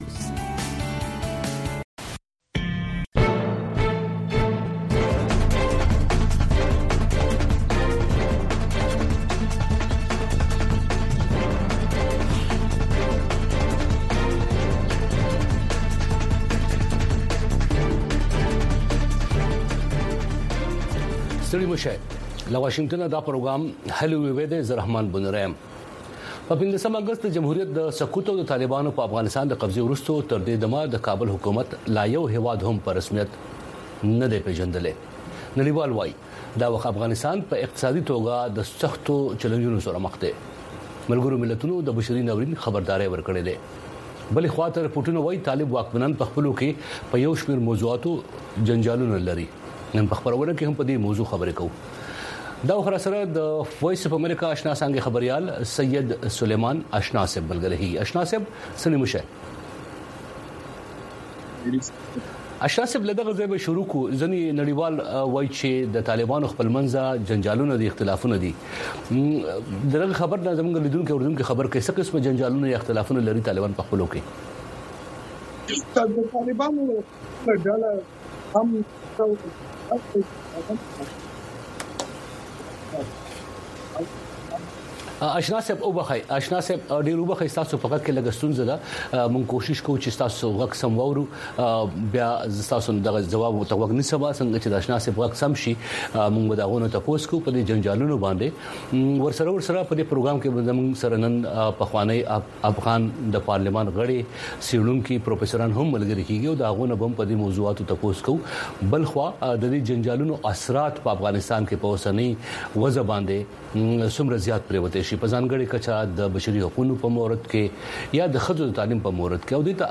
دې یو ساعته پروگرام کې تاسو خبرونه او د هغې وروسته، په یو شمېر نړیوالو او سیمه ایزو موضوگانو د میلمنو نه پوښتنې کولی شۍ.